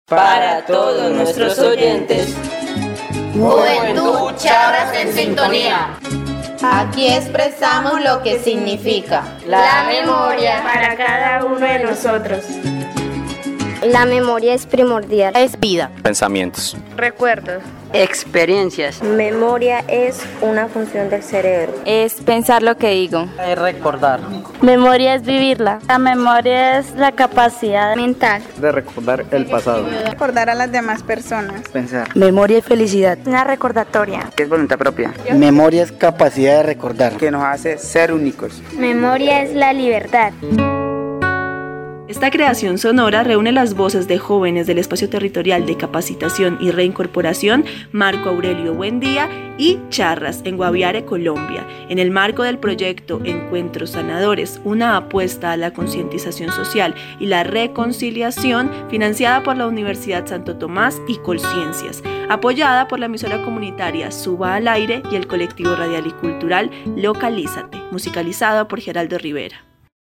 Los jóvenes del grupo ''Juventud Charras en Sintonía'' exponen qué significa para ellos ''La Memoria'' a través de una palabra.